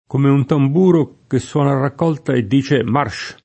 k1me un tamb2ro, ke SSU0na a rrakk0lta e dde: m#] (De Sanctis)